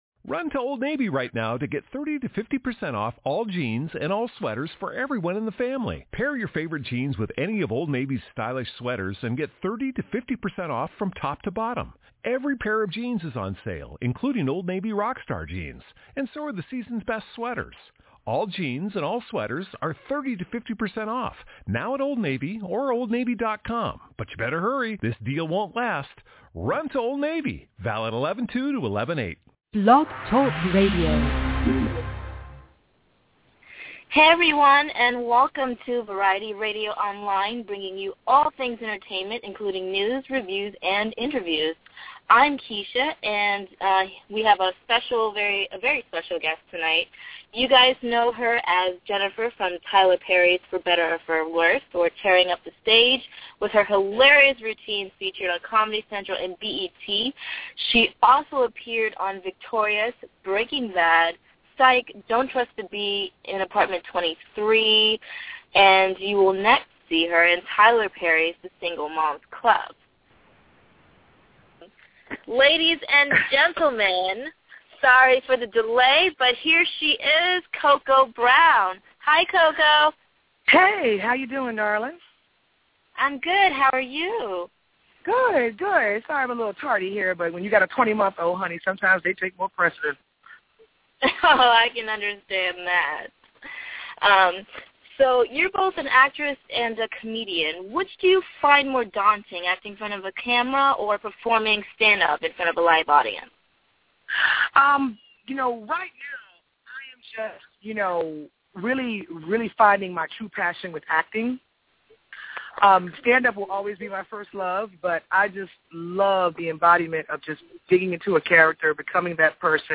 Cocoa Brown – The Single Mom’s Club – Interview